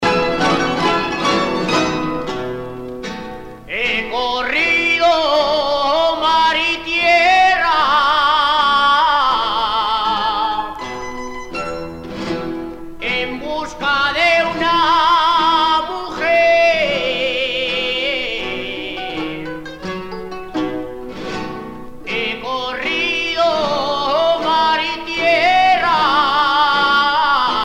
danse : jota (Espagne)
Pièce musicale éditée